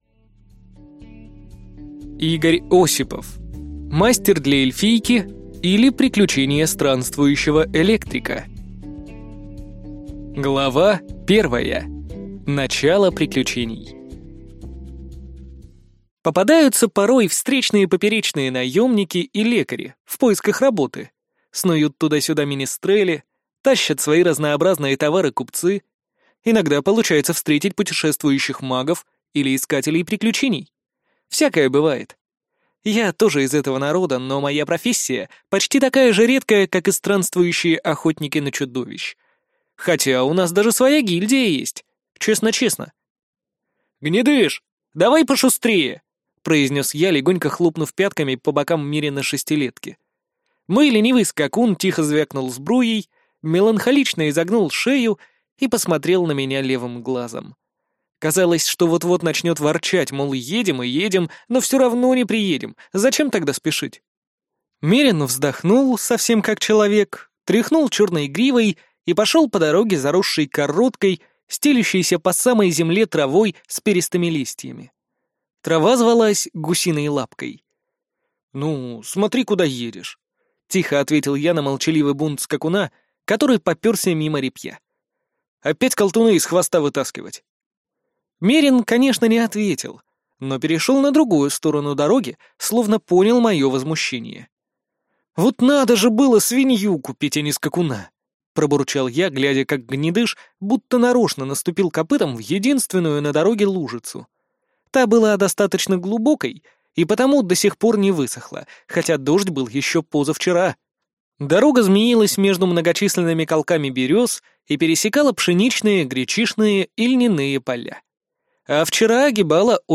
Аудиокнига Мастер для эльфийки, или приключения странствующего электрика | Библиотека аудиокниг